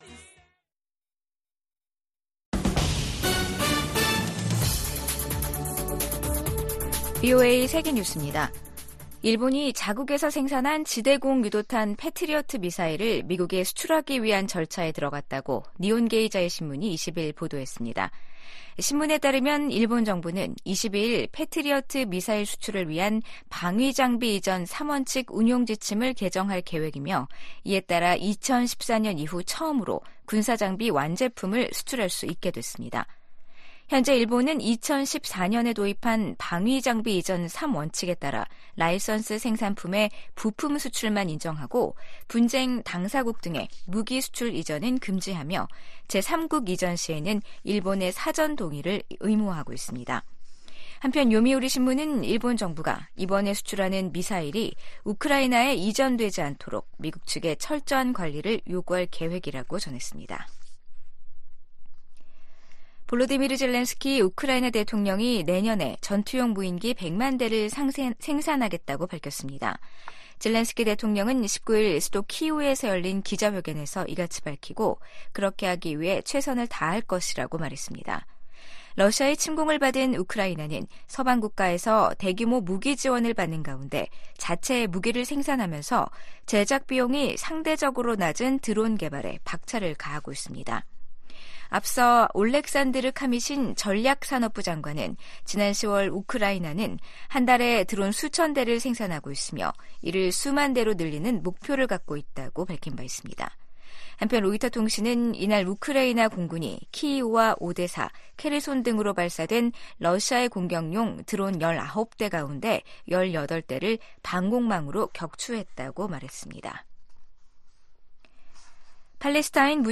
VOA 한국어 간판 뉴스 프로그램 '뉴스 투데이', 2023년 12월 20일 3부 방송입니다. 유엔 안보리가 북한의 대륙간탄도미사일(ICBM) 발사에 대응한 긴급회의를 개최했지만 구체적 대응 조치에 합의하지 못했습니다. 유럽연합(EU)은 북한의 연이은 탄도미사일 발사를 국제 평화에 대한 위협으로 규정하며 강력히 규탄했습니다.